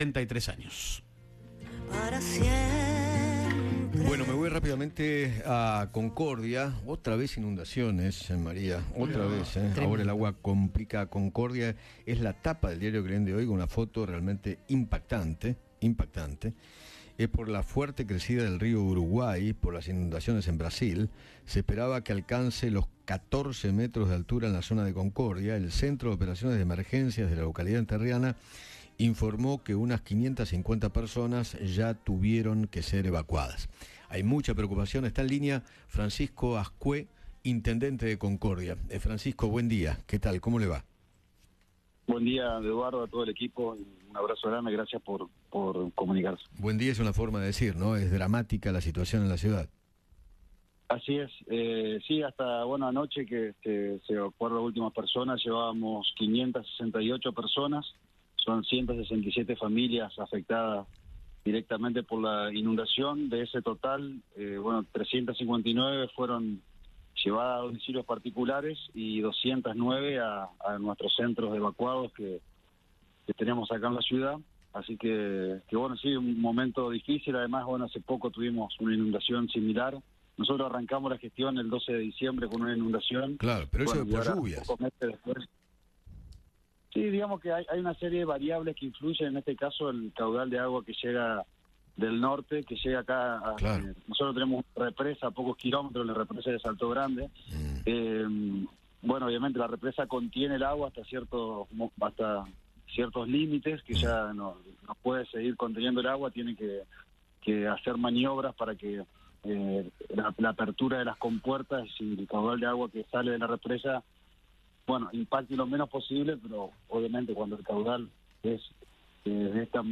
Francisco Azcué, intendente de la ciudad de Concordia, habló con Eduardo Feinmann sobre las inundaciones provocadas por la crecida del Río Uruguay.